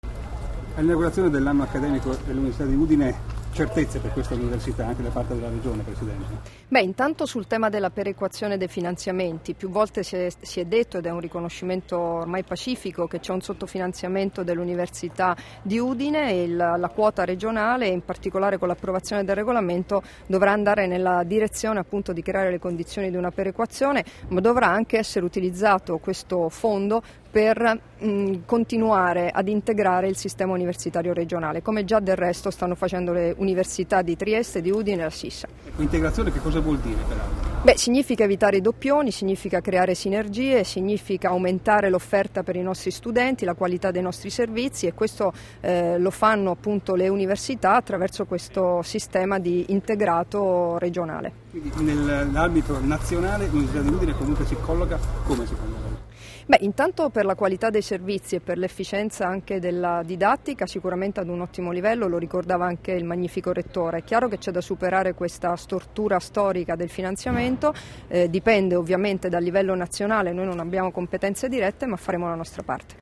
Dichiarazioni di Debora Serracchiani (Formato MP3) [1169KB]
all'inaugurazione del XXXVII Anno Accademico dell'Università, rilasciate a Udine il 19 gennaio 2015